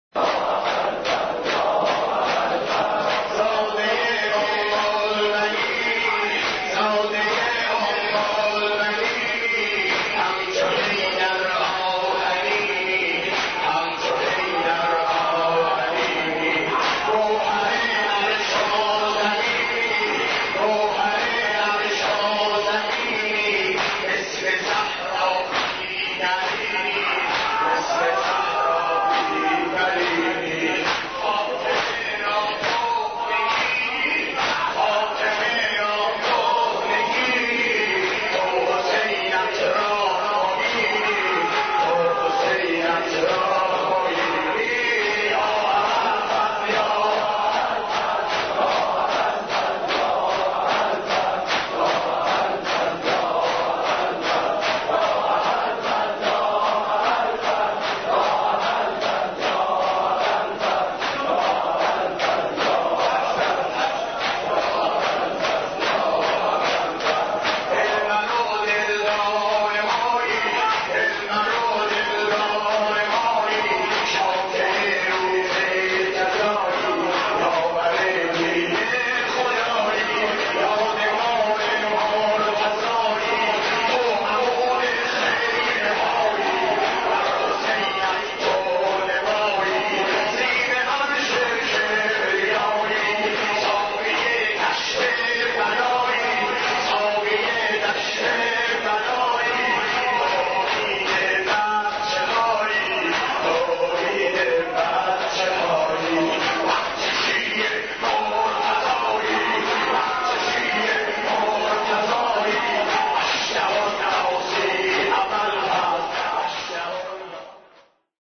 حضرت عباس ع ـ شور 18